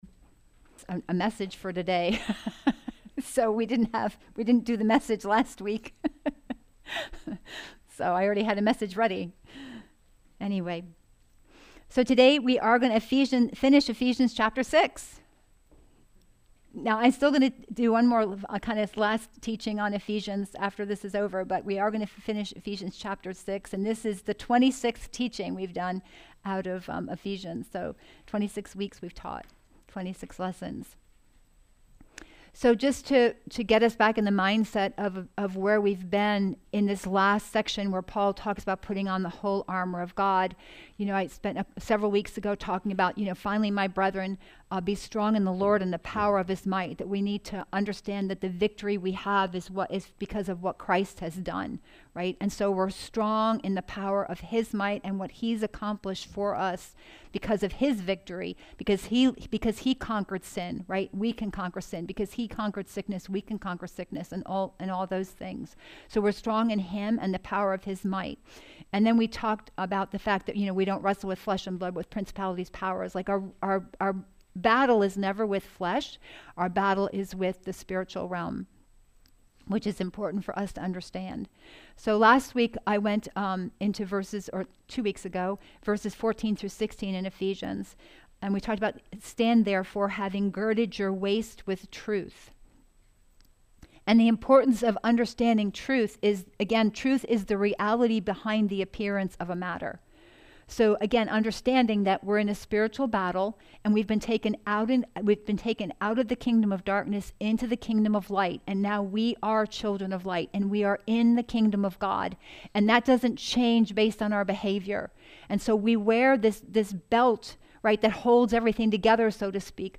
Messages | Kingdom Life Church International